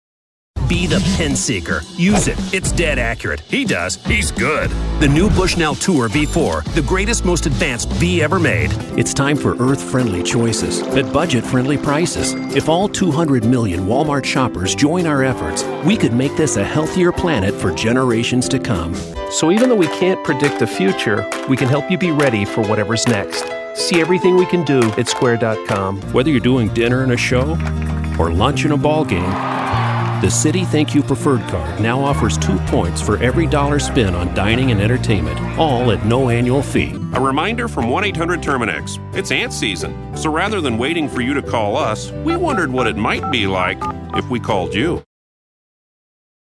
Male Voice Talent
Exciting, High-Energy, Strong: A powerful voice that's great for a wide range of uses. An excellent choice if you need a voice over said loudly and with conviction.
Commercial